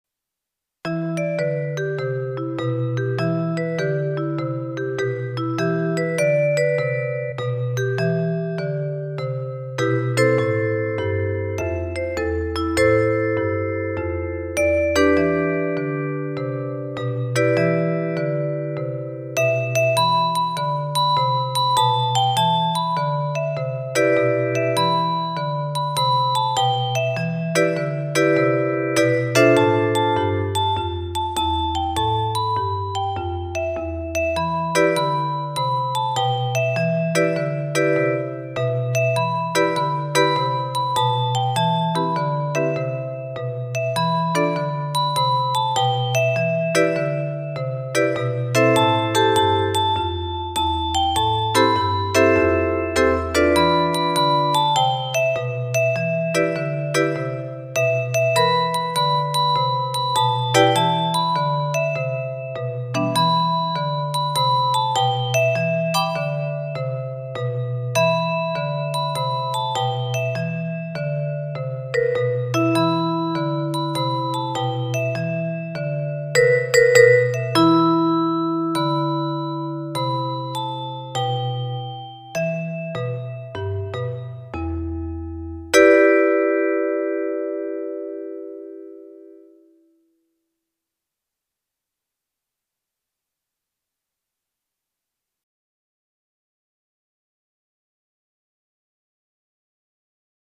It was here that we decided that in the interest of time the children would play non-tuned percussion instruments (adding an African sound) and I would create computerized accompaniments.
Accompaniment, MP3 Listen to the audio file